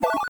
Magic2.wav